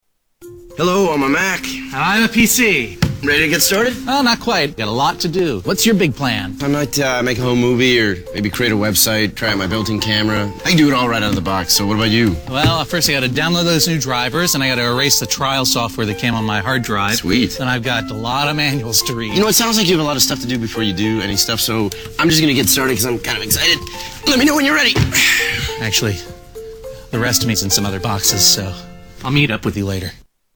Tags: Media Apple Mac Guy Vs. PC Guy Commercial Justin Long John Hodgeman